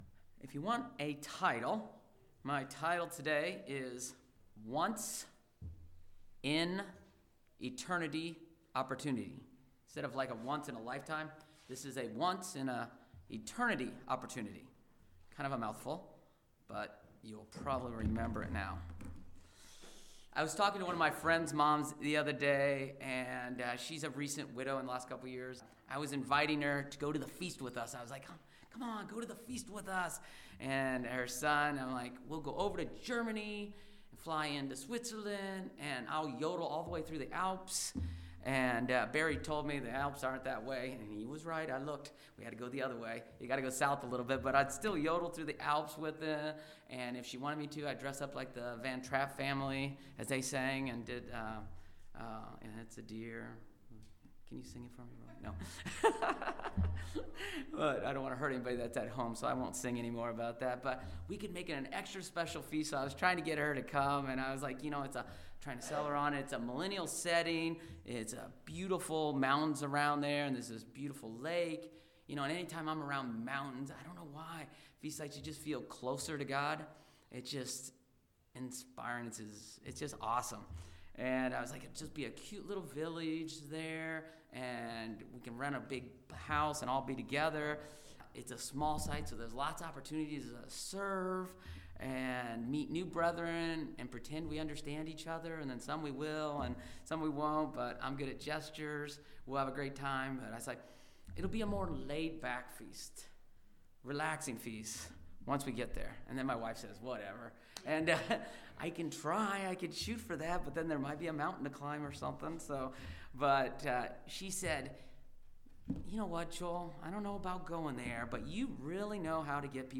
This sermon explores how valuable our spiritual inheritance and birthright are to us.
Given in Ft. Wayne, IN